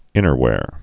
(ĭnər-wâr)